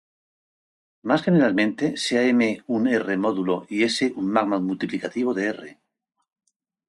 módulo a 🐌 Meaning Concepts Synonyms Translations Notes Practice pronunciation Extra tools module Frequency C2 Hyphenated as mó‧du‧lo Pronounced as (IPA) /ˈmodulo/ Etymology From Latin modulus.